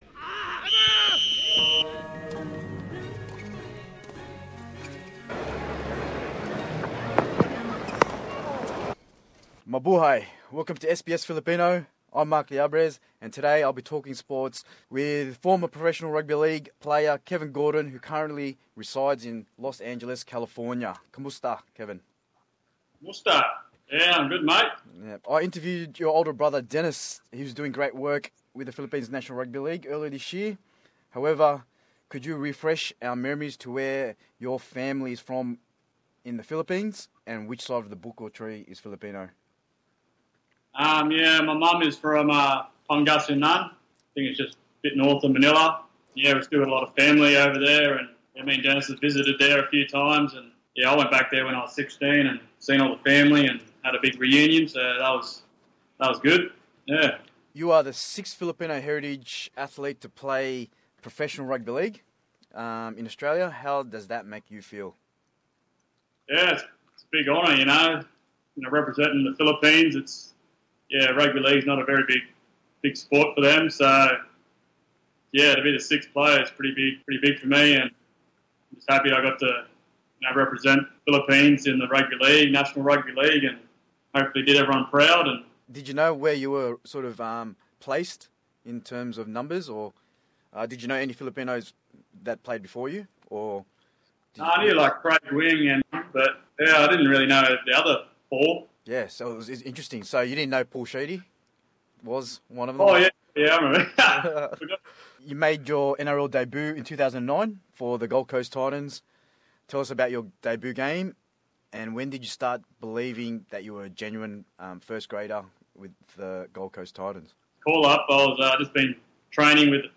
Larawan: screen grab mula sa the skype interview